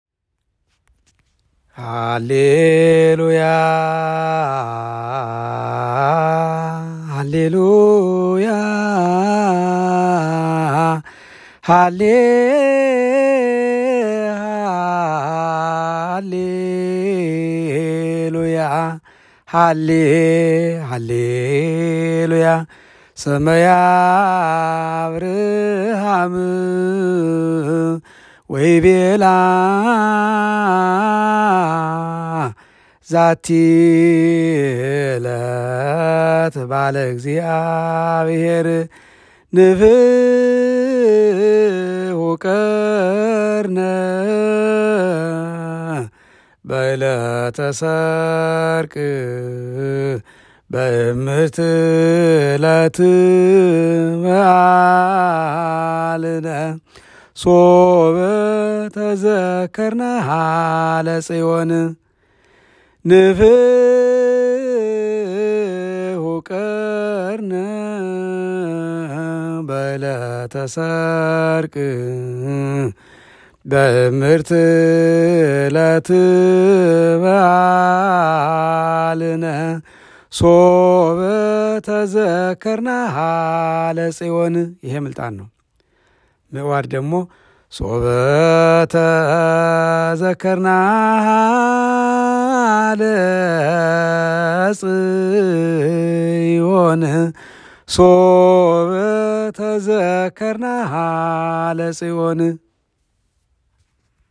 የዜማ ትምህርት